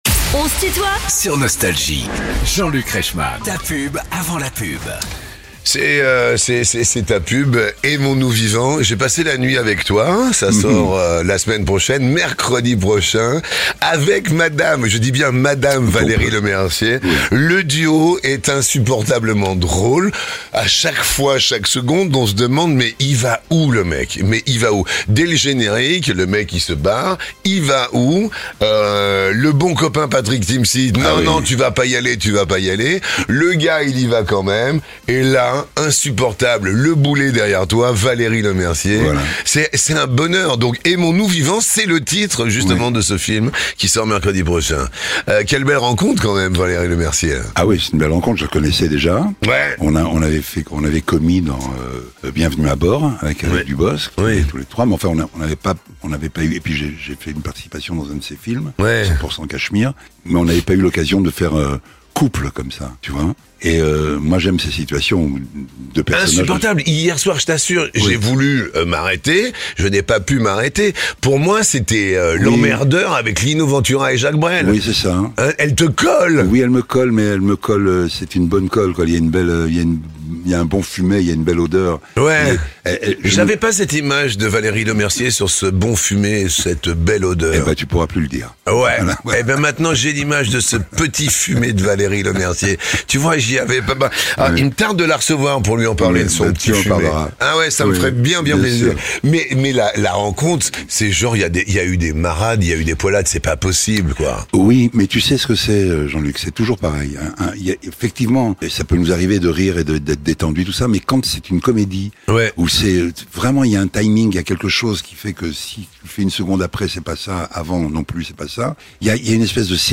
Beschreibung vor 8 Monaten A l'occasion de la sortie du film "Aimons-nous vivants" de Jean-Pierre Améris dont il partage l'affiche avec Valérie Lemercier, l’acteur chanteur Gérard Darmon est l'invité de "On se tutoie ?..." avec Jean-Luc Reichmann, vendredi 11 avril, de 19h à 20h, pour évoquer son actualité et partager les anecdotes de prolifique carrière.